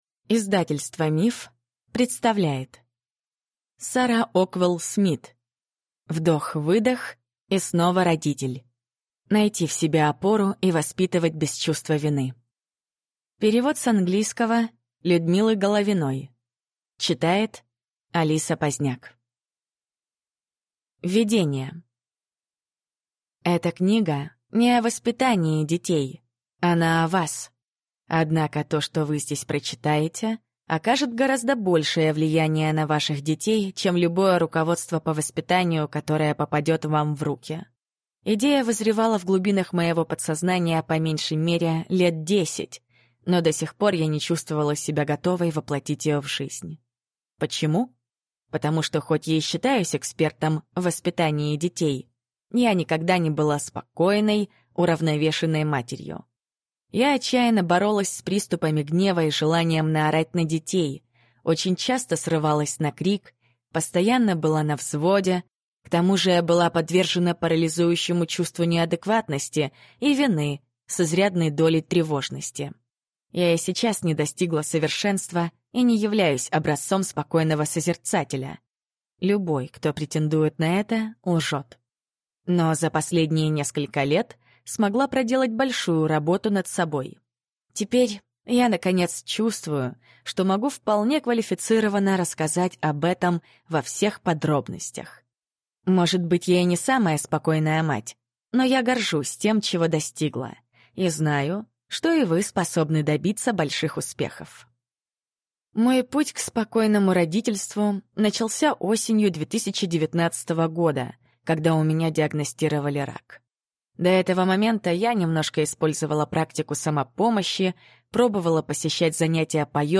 Аудиокнига Вдох-выдох – и снова родитель. Найти в себе опору и воспитывать без чувства вины | Библиотека аудиокниг